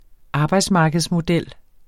arbejdsmarkedsmodel substantiv, fælleskøn Bøjning -len, -ler, -lerne Udtale [ ˈɑːbɑjds- ] Betydninger model for arbejdsmarkedet der udgør rammen for forhandlinger vedrørende overenskomster, løn, arbejdsvilkår mv.